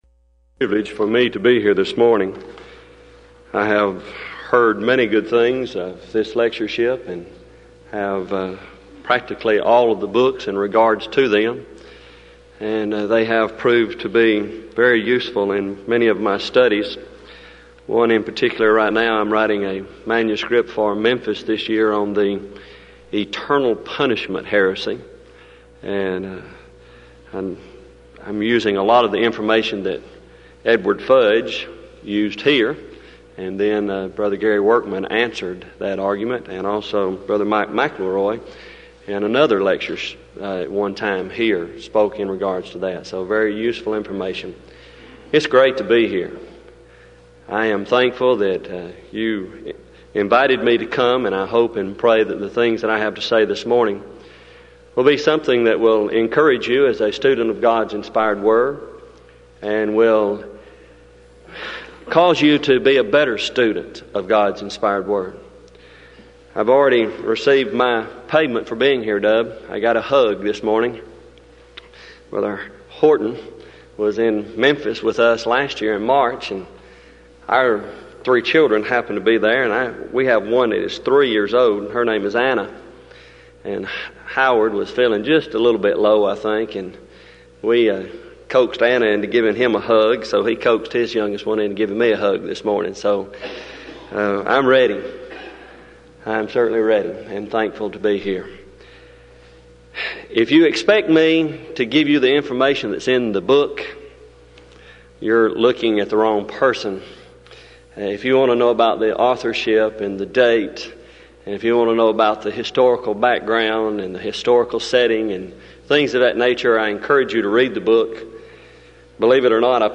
Event: 1994 Denton Lectures Theme/Title: Studies In Joshua, Judges And Ruth